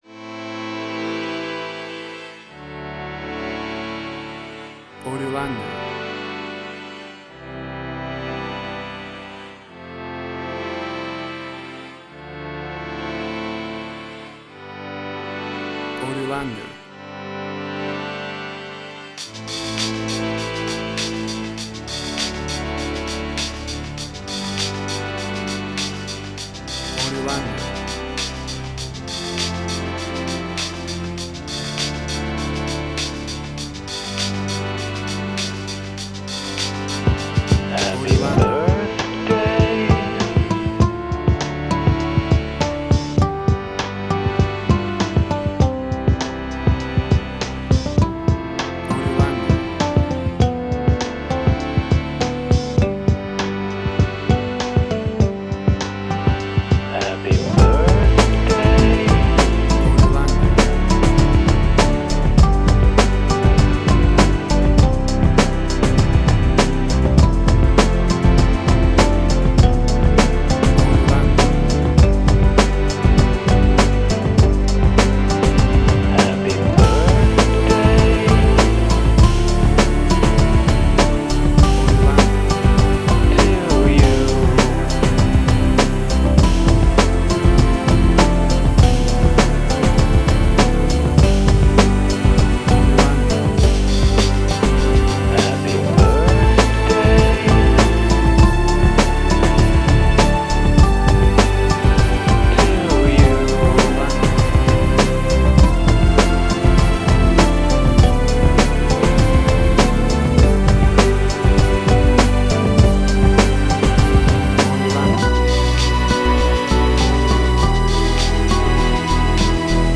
emotional music
Tempo (BPM): 100